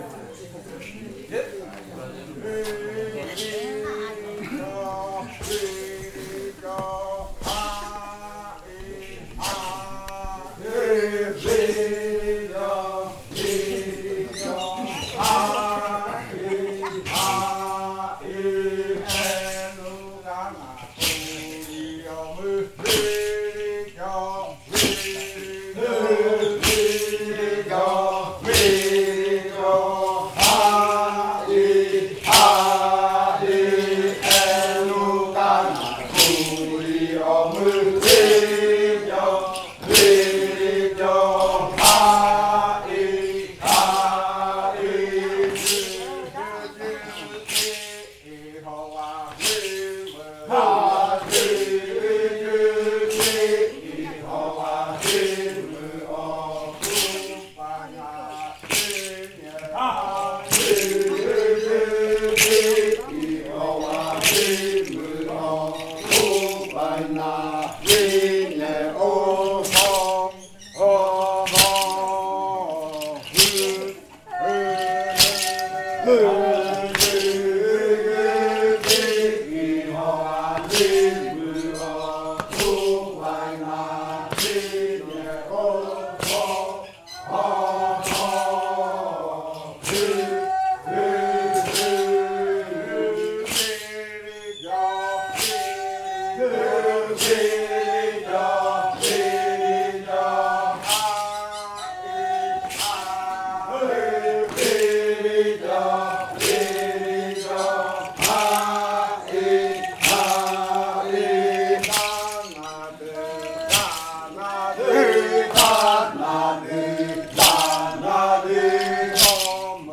Primer canto de entrada (arrimada) de la variante muruikɨ
Leticia, Amazonas
con el grupo danzando (en Nokaido).
with the group dancing (in Nokaido). This song is part of the collection of songs from the yuakɨ murui-muina ritual (fruit ritual) of the Murui people, a collection that was compiled by the Kaɨ Komuiya Uai Dance Group with the support of a solidarity outreach project of the Amazonia campus of UNAL.